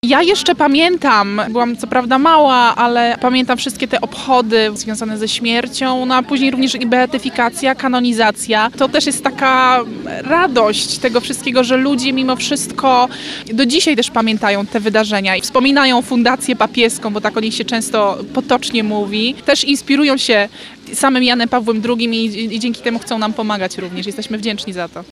Pogoda nie przeszkodziła organizatorom i mieszkańcom Lublina w spotkaniu się na placu Litewskim z okazji XVII Dnia Papieskiego.